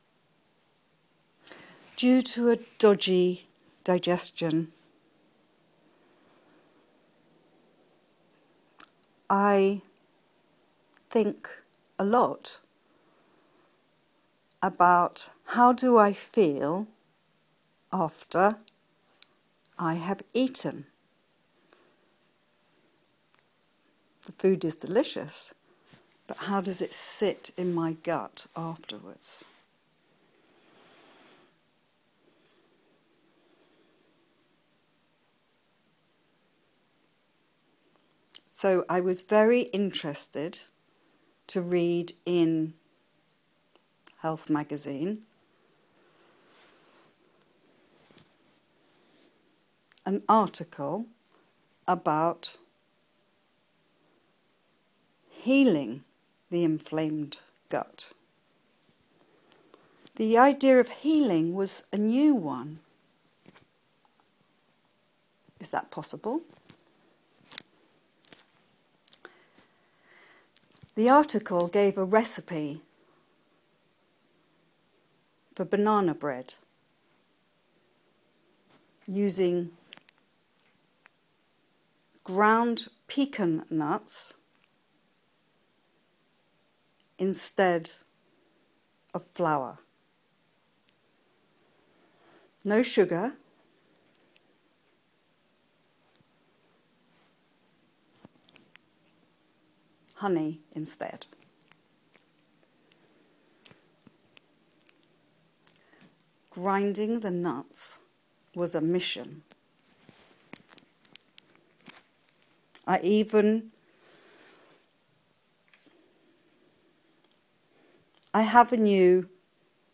Experiment: Spoke a first draft instead of writing it.